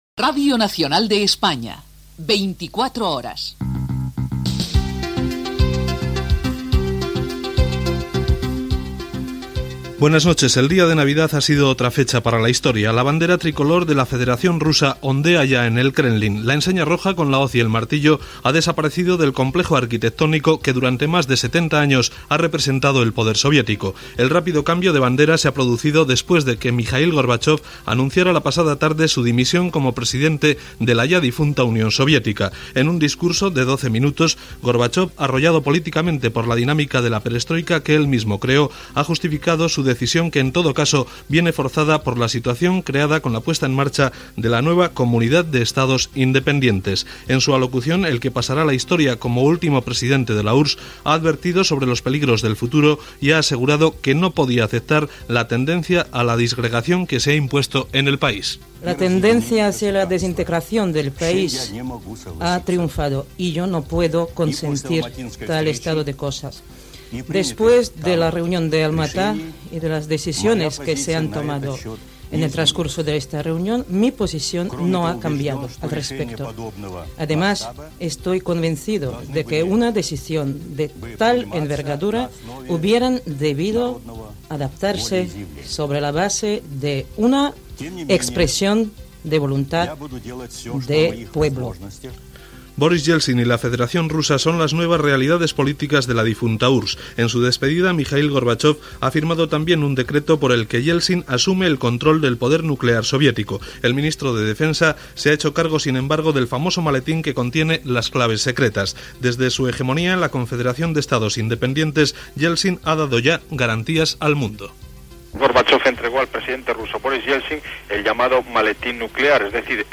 14d45a670827c484e40a6bcb553509f29e90951c.mp3 Títol Radio Nacional de España Emissora Radio Nacional de España Barcelona Cadena RNE Titularitat Pública estatal Nom programa 24 horas Descripció Careta del programa, sumari informatiu i informació de la dimissió del president Mikhaïl Gorbatxov i de la dissolució de la Unió de Repúbliques Socialistes Soviètiques, que va culminar amb la independència de les quinze Repúbliques que la formaven. La figura política de Borís Ieltsin.
Gènere radiofònic Informatiu